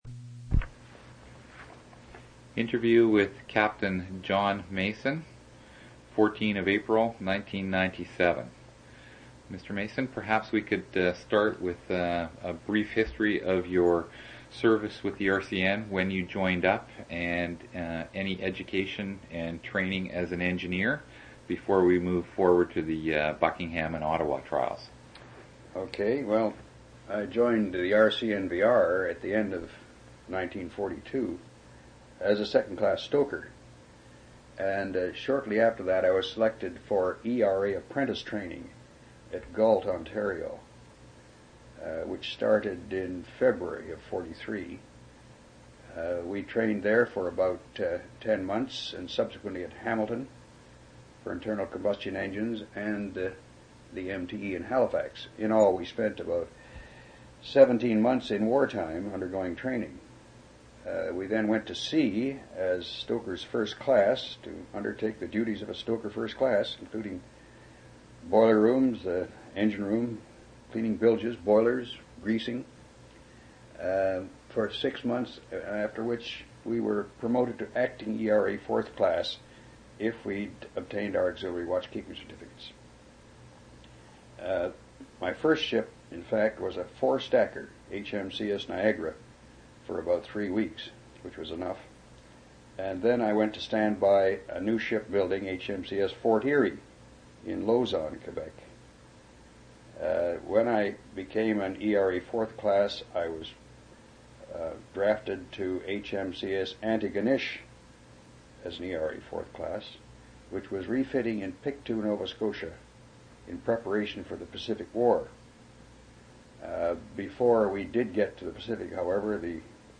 Canadian Military Oral Histories
One original audio cassette in Special Collections.
oral histories (literary genre)